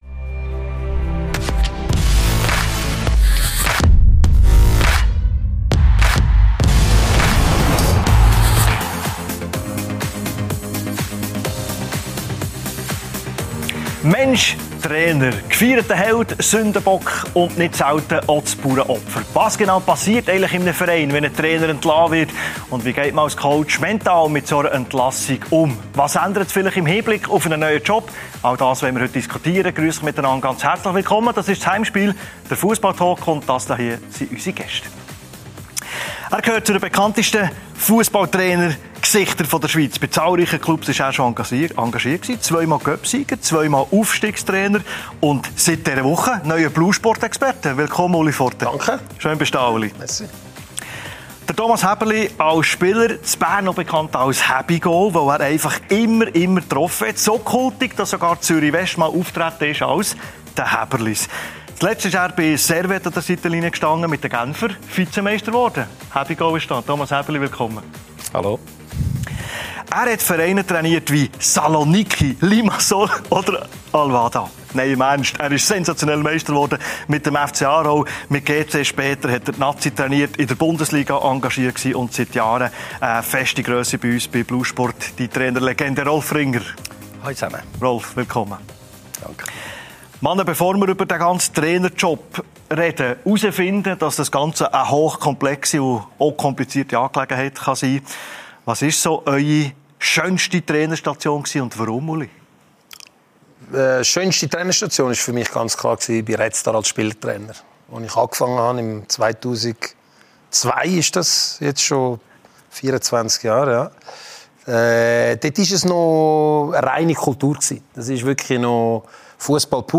Der Fussball-Talk Podcast